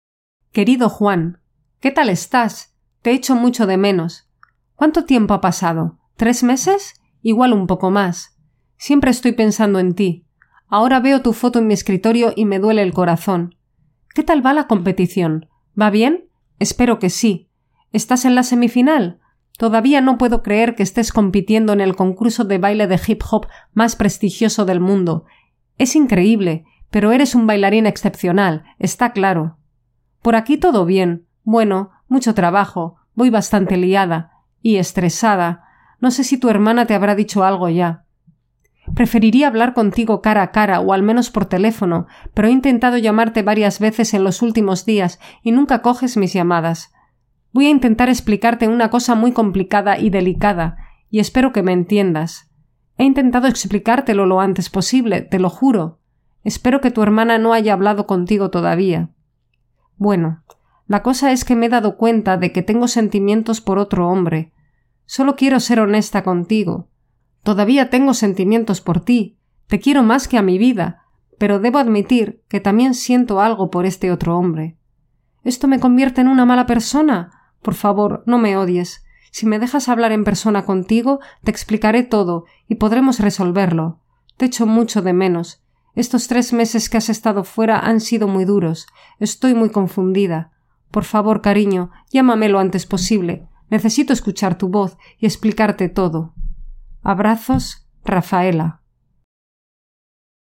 18.-Listening-Practice-–-Writing-a-letter.mp3